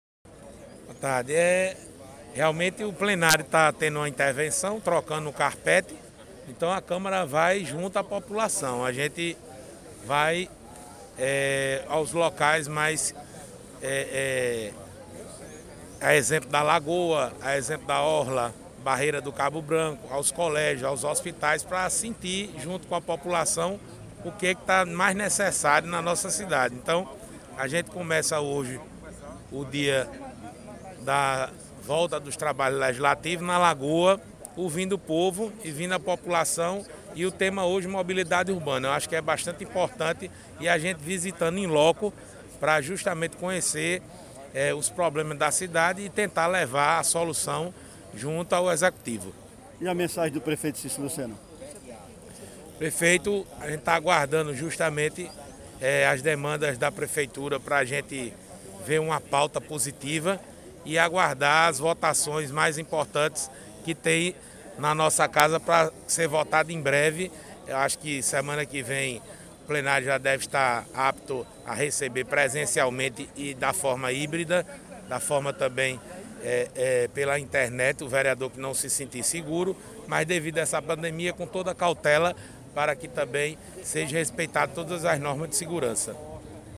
Abaixo a fala do presidente da CMJP, vereador Dinho.